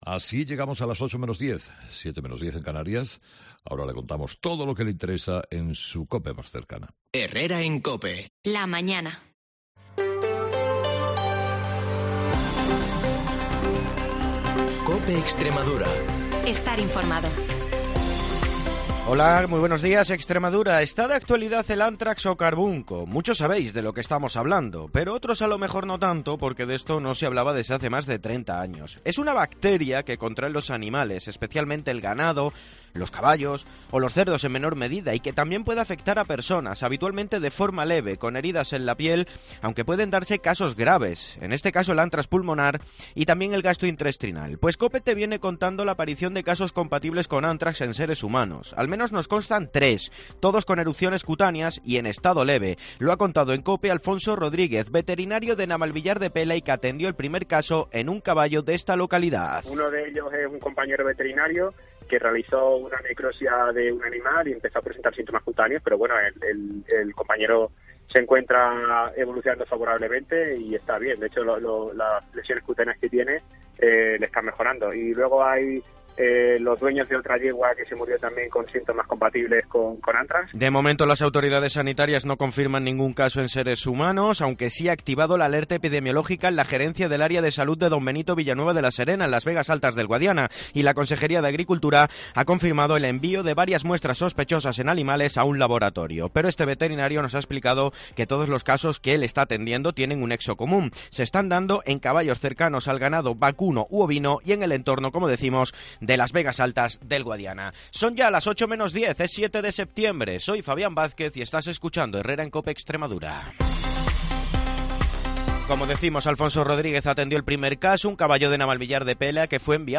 de lunes a viernes a las 7:20 y 7:50 horas el informativo líder de la radio en la región